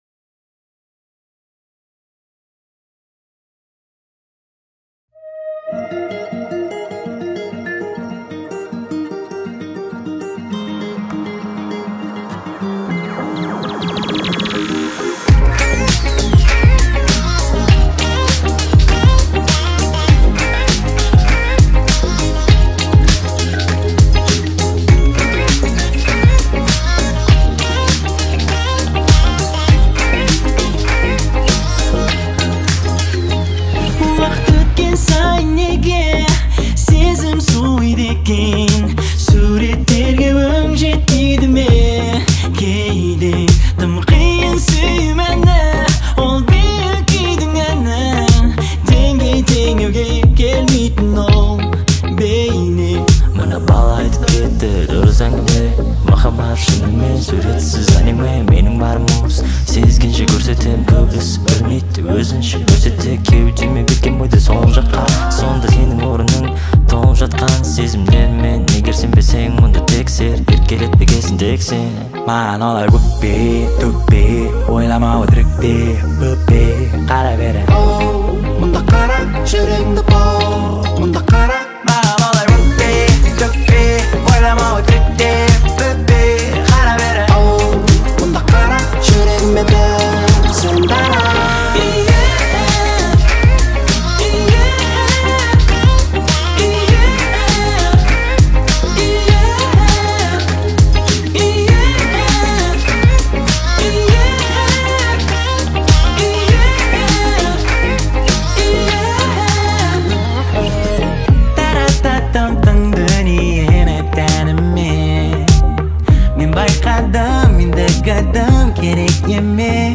представляет собой яркий пример жанра Q-pop
современными электронными битами и мелодичными припевами